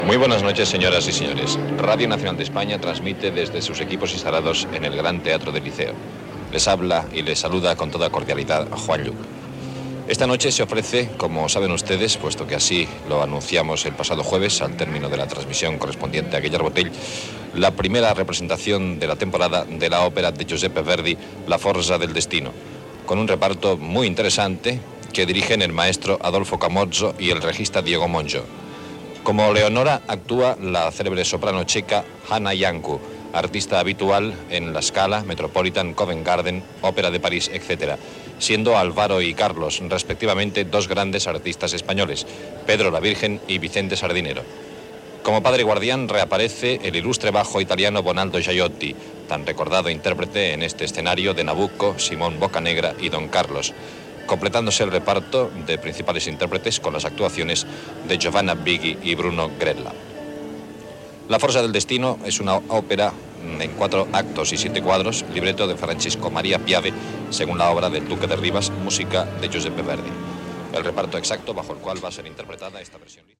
Inici de la transmissió, des del Gran Teatre del Liceu de Barcelona, de l'òpera "La forza del destino", de Verdi, amb el repartiment de la funció.
Musical